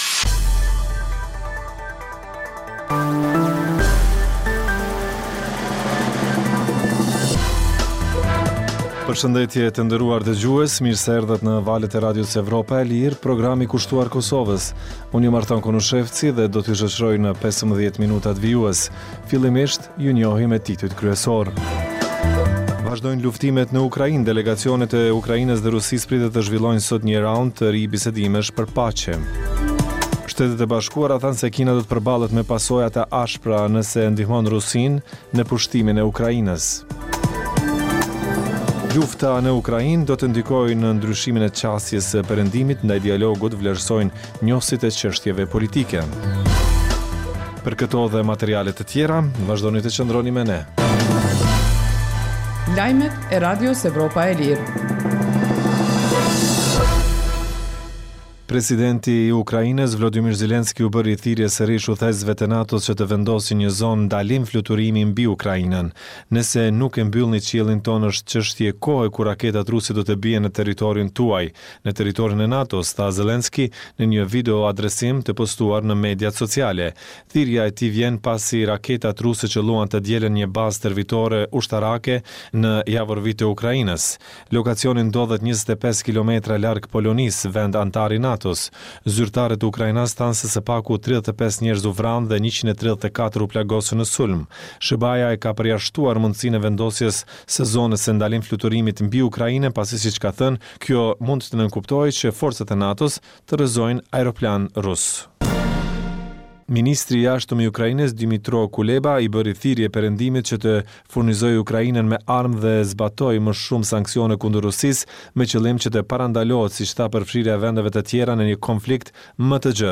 Emisioni i mesditës fillon me buletinin e lajmeve që kanë të bëjnë me zhvillimet e fundit në Kosovë, rajon dhe botë. Në këtë emision sjellim raporte dhe kronika të ditës, por edhe tema aktuale nga zhvillimet politike dhe ekonomike.